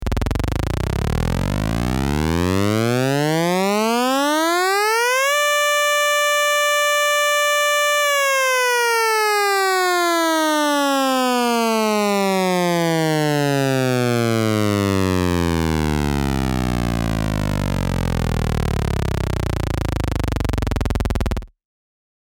Звуки 8 бит
Сирена 8 бит